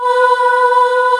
M1 VOICES #3.wav